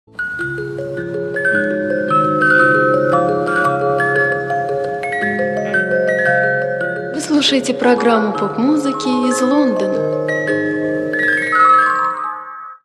МузЗаставки